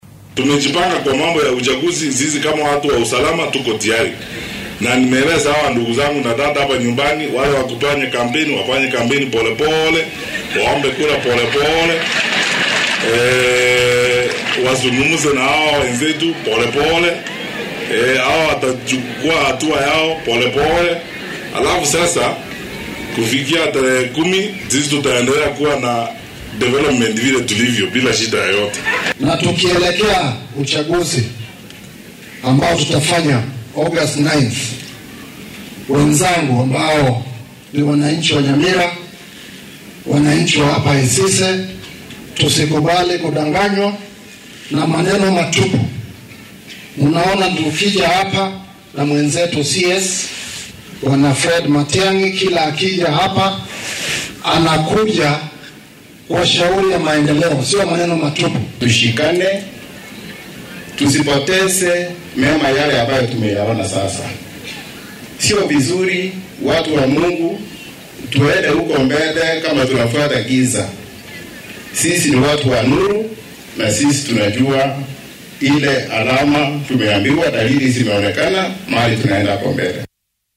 Waxaa uu siyaasiyiinta ugu baaqay inay nabadda ku dadalaan ololahoodana si nabad ah u sameystaan. Dr .Matiang’i ayaa intaasi ku daray in la adkeeyay ammaanka xuddudaha dalka. Hadalkan ayuu jeediyay wasiirku xilii uu kulan amni ah ku qabanayay ismaamulka Nyamira.